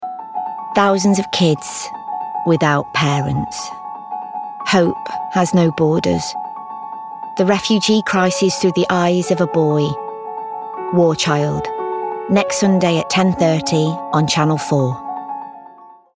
***NEW ARTIST*** | 50s | Warm, Quirky & Natural
Voice reel